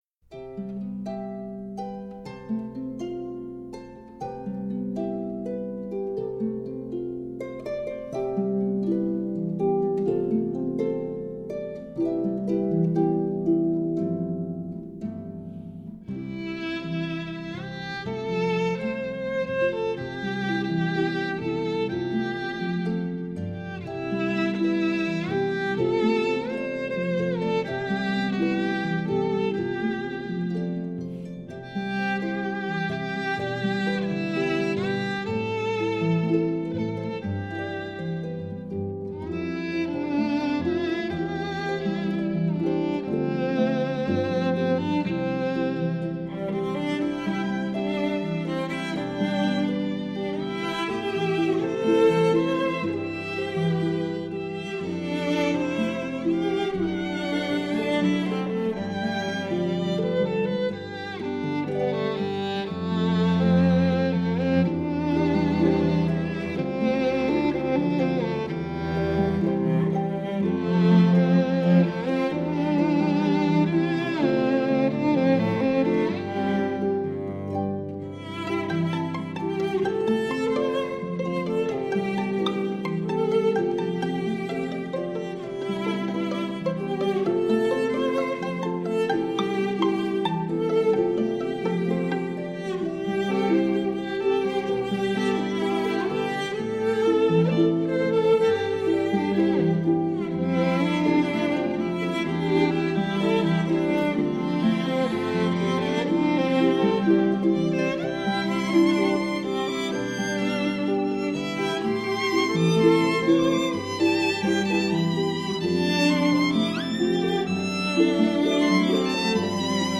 12首传唱不坠的经典中国民谣　最具质感的原音重现
江苏民歌